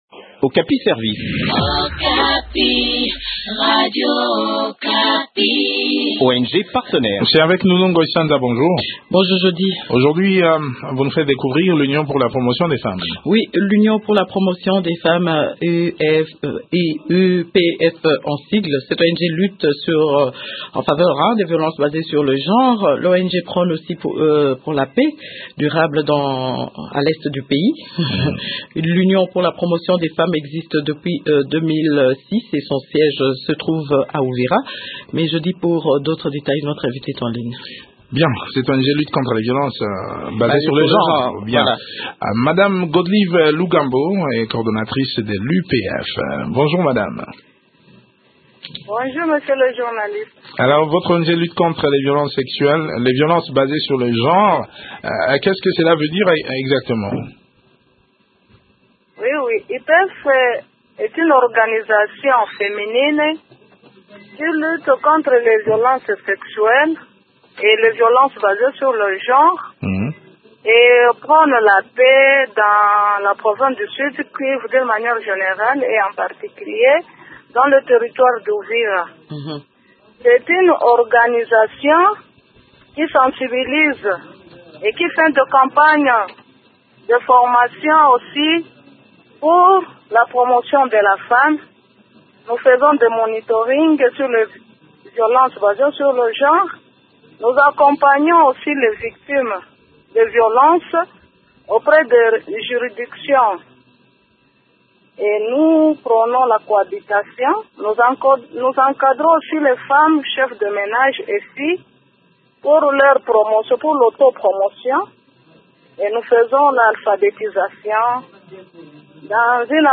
répond aux questions de